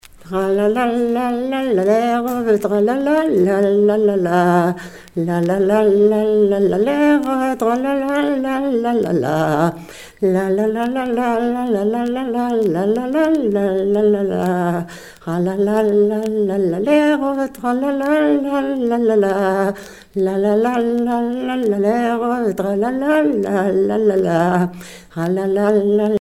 danse : mazurka
Pièce musicale éditée